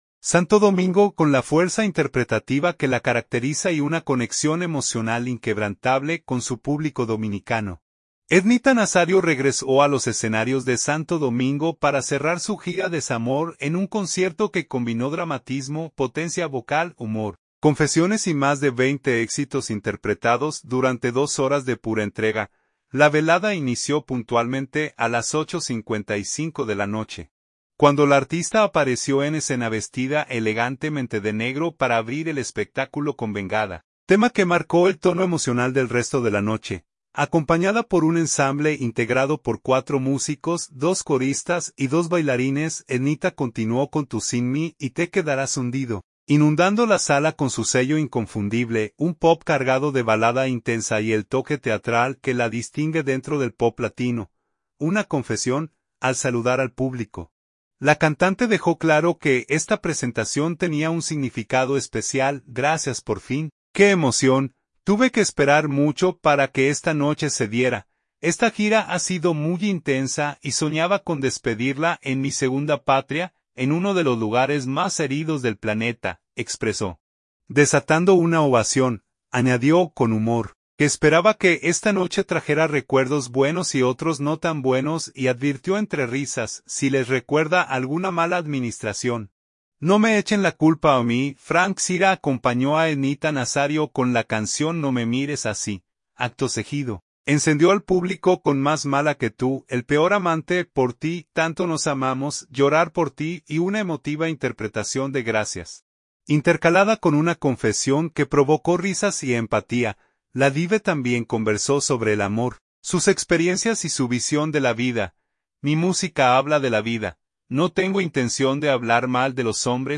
La boricua se presentó en el Teatro Nacional.